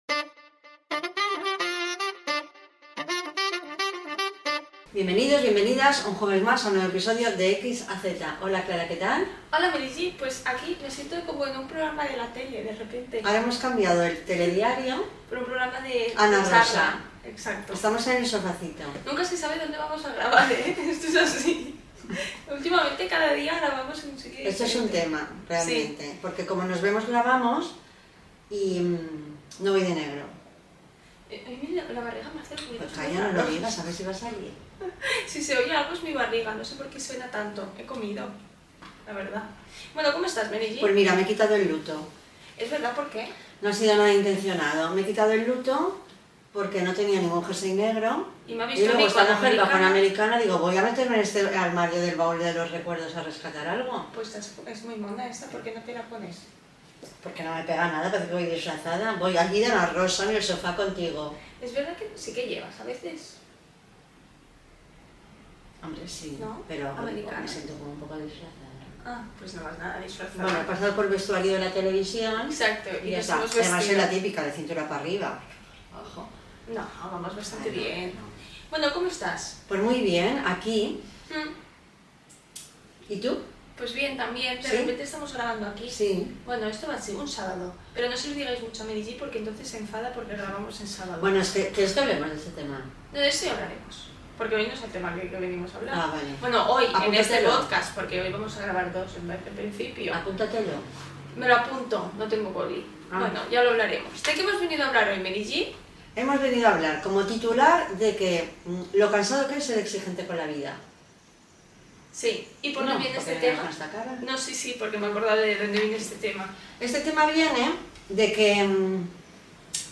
(Perdonad el audio, no se grabó bien con el micro)